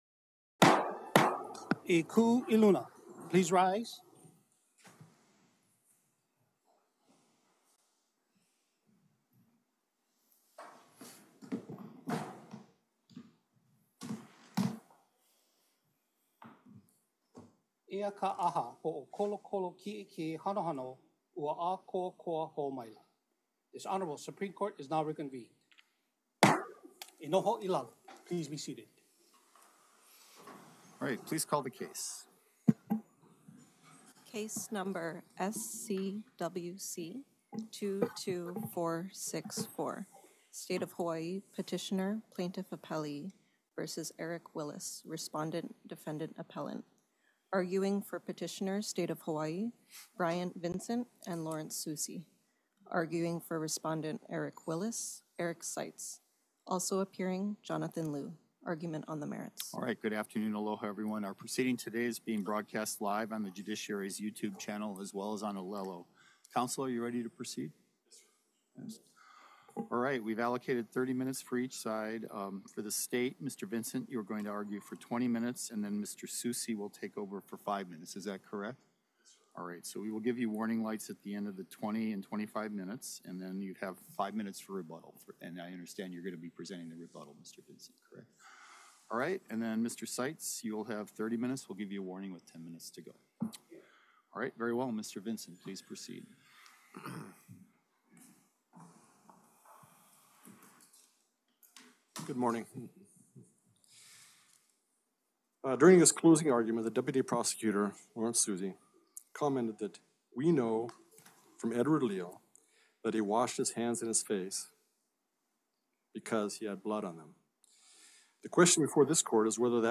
The above-captioned case has been set for oral argument on the merits at: Supreme Court Courtroom Ali‘iōlani Hale, 2nd Floor 417 South King Street Honolulu, HI 96813